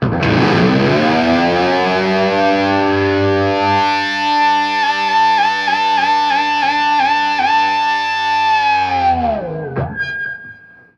DIVEBOMB 2-R.wav